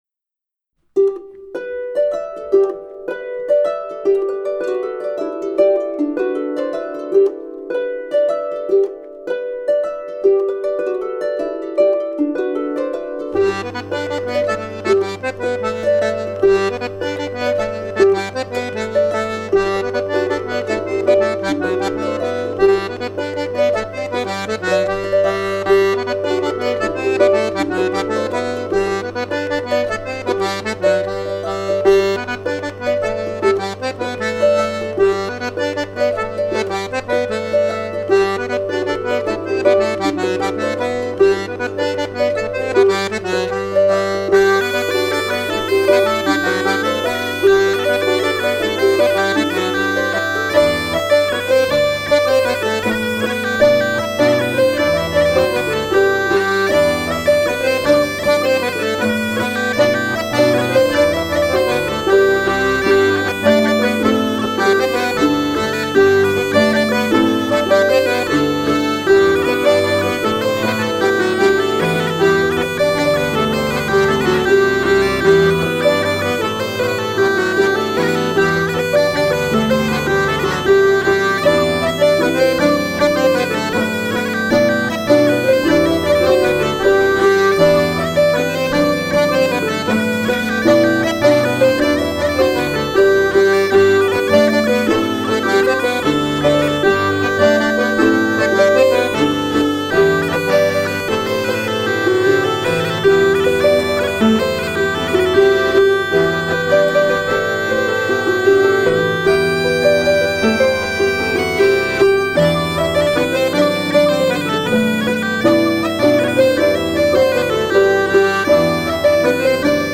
harpe & chant
cornemuse & violon